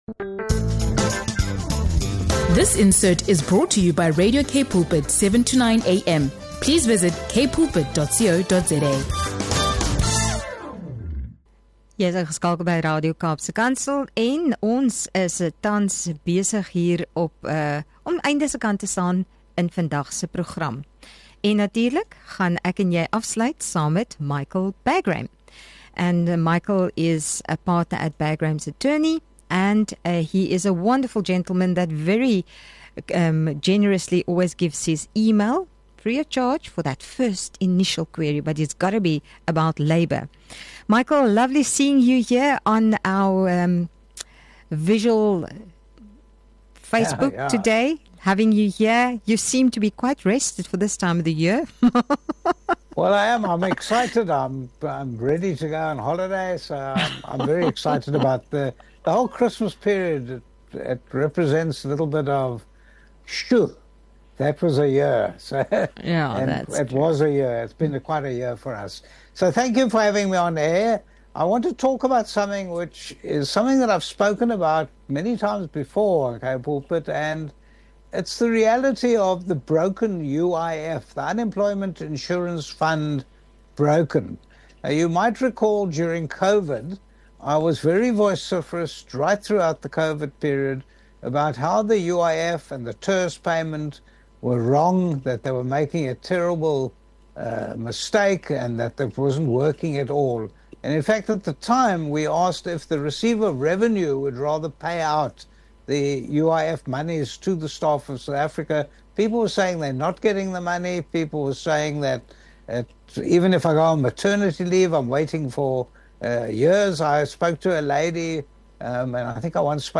Join us as we sit down with Michael Bagraim, partner at Bagraim Attorneys, to discuss the ongoing challenges with South Africa’s Unemployment Insurance Fund (UIF) and labor issues.